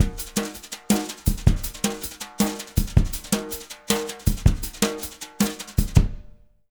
160JUNGLE5-L.wav